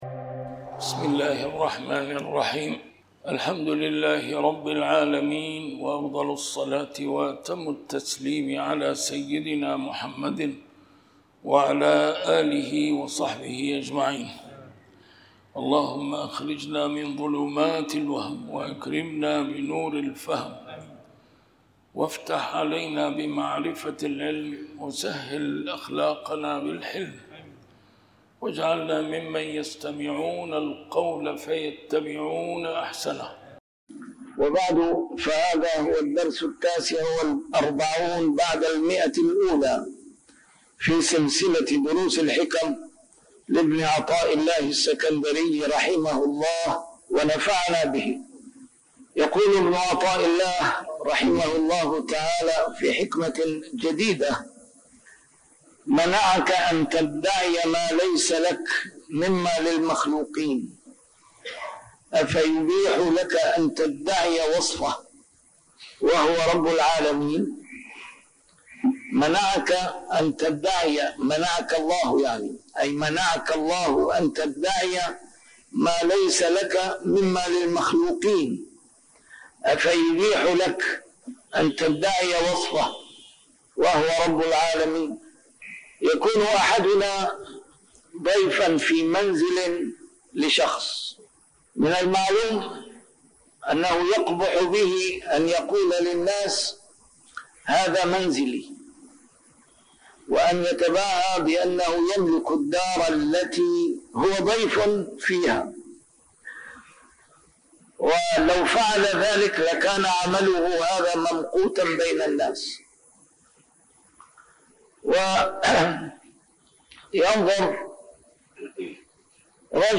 A MARTYR SCHOLAR: IMAM MUHAMMAD SAEED RAMADAN AL-BOUTI - الدروس العلمية - شرح الحكم العطائية - الدرس رقم 149 شرح الحكمة 126